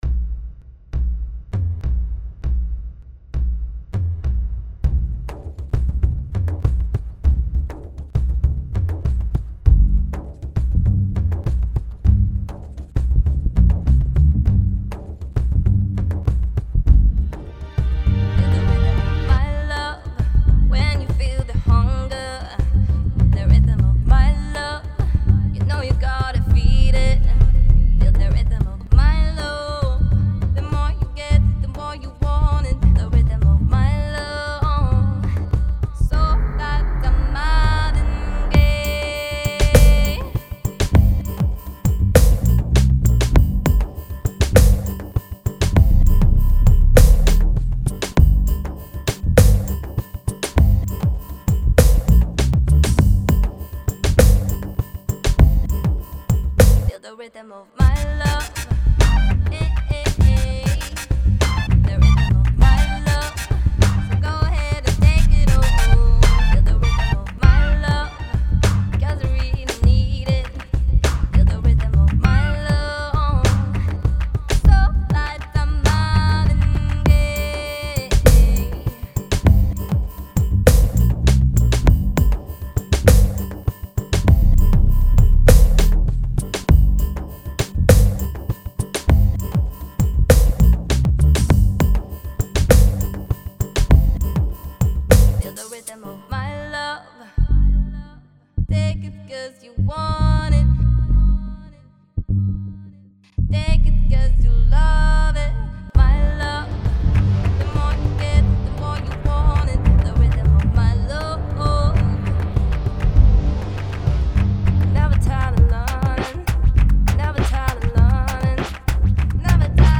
Style: EDM, Pop, Slow/Mid-tempo